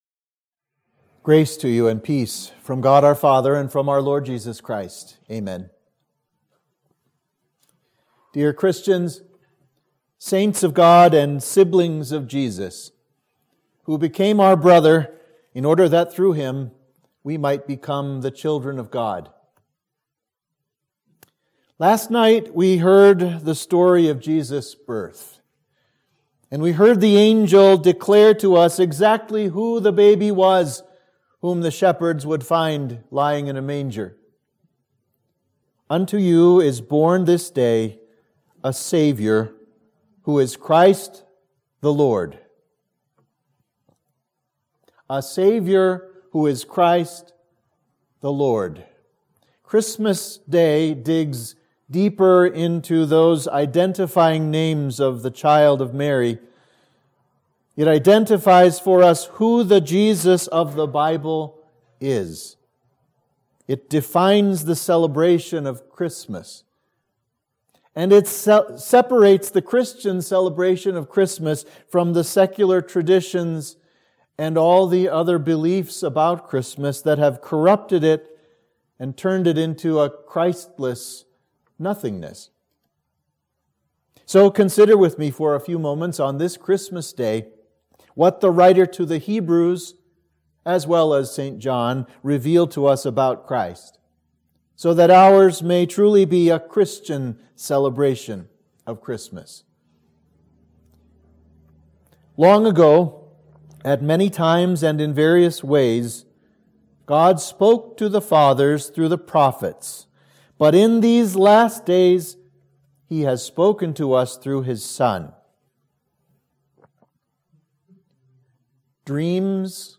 Sermon for Christmas Day